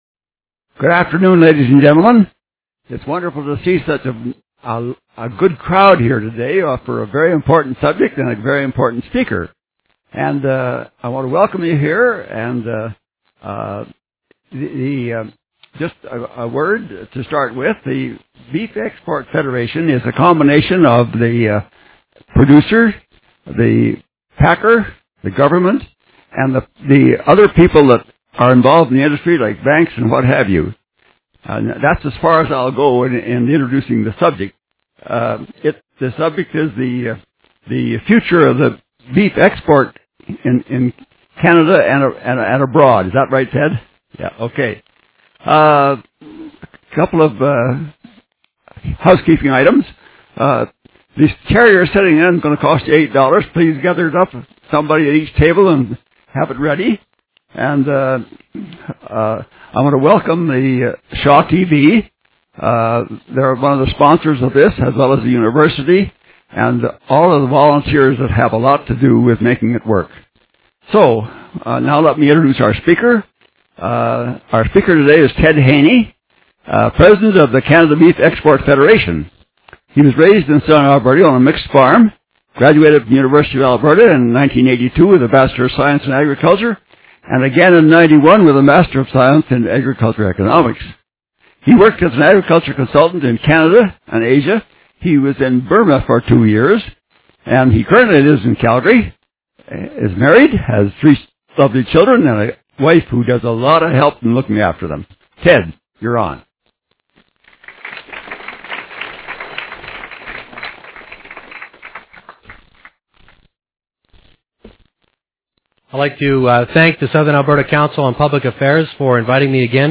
Download the Audio of Audience Q&A from this session.